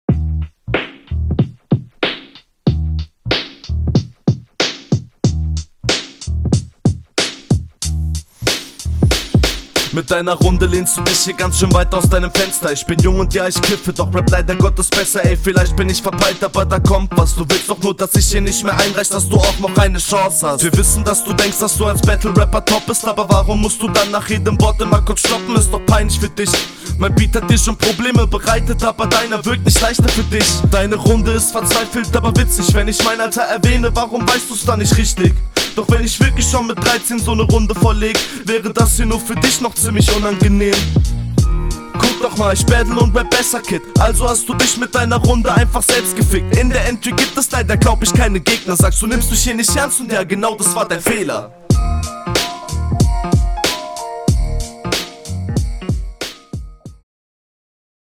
Flowlich ist das hier auch wieder sehr fresh, Stimmeinsatz kommt auch sehr gut.
Ufff, du kommst direkt viel routinierter auf dem Beat.